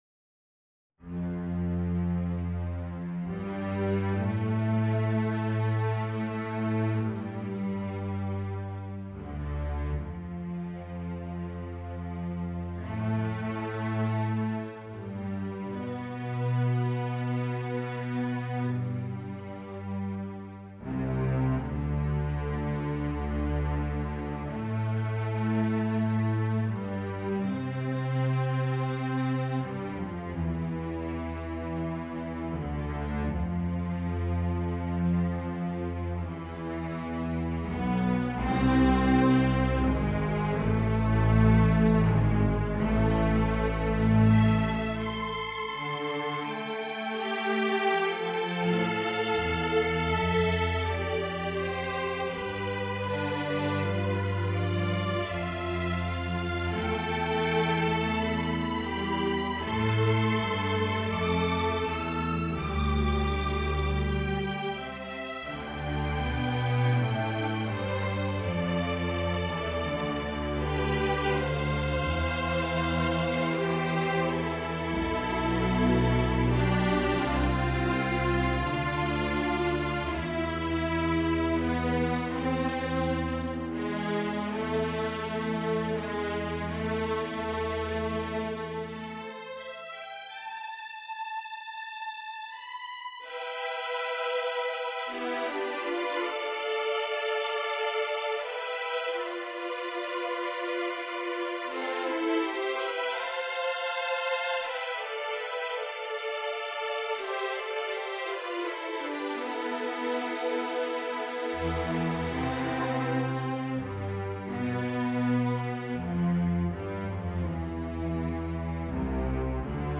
String Orchestra
Violin I
Violin II
Violin III
Viola
Violoncello
Double Bass